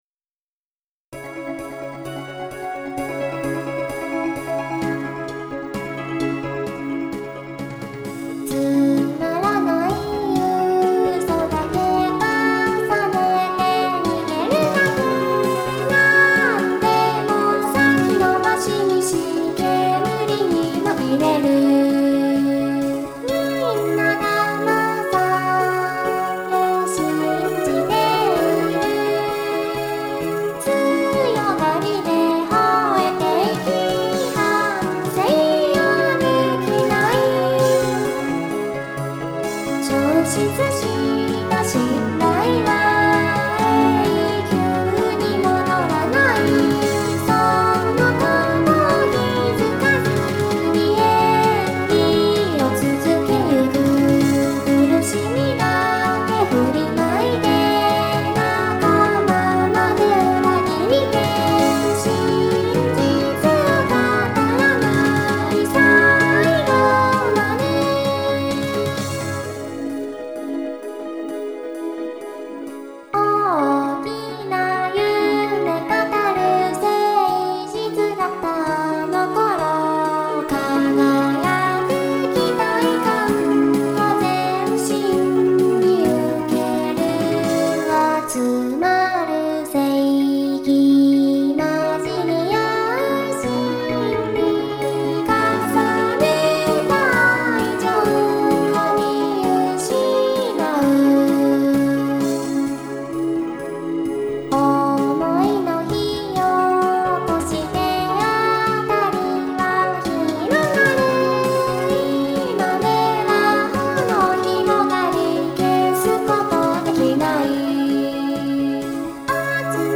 〜ボーカル版〜